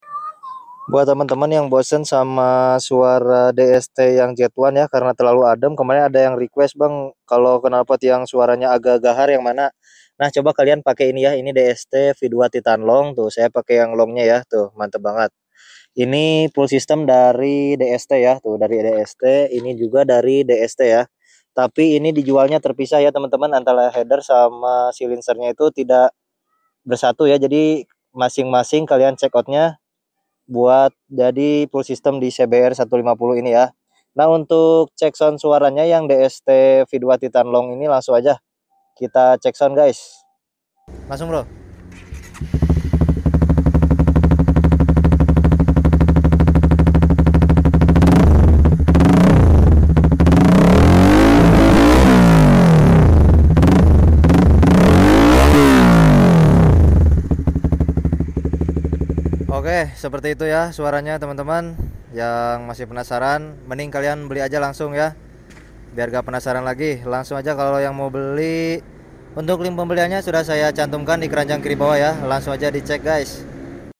Knalpot DST V2 TITAN LONG Sound Effects Free Download